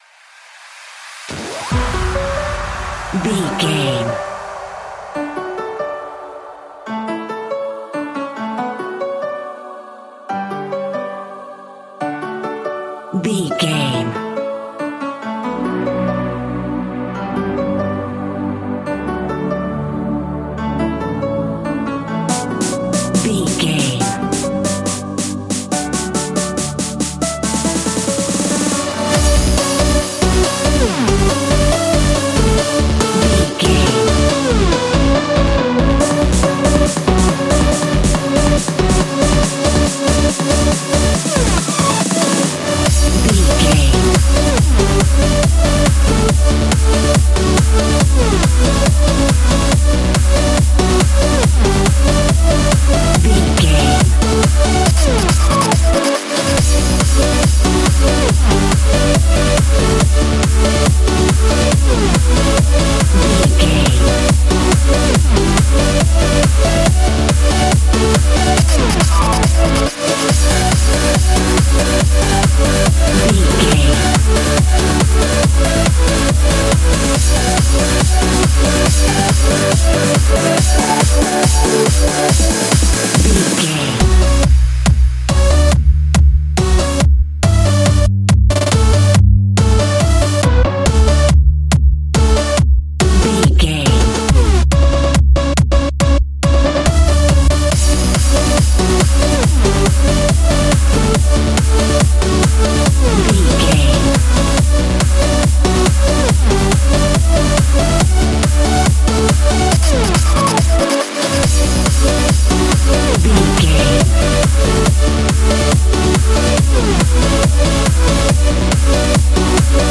Aeolian/Minor
Fast
aggressive
dark
energetic
intense
epic
frantic
strings
synthesiser
drum machine
breakbeat
synth leads
synth bass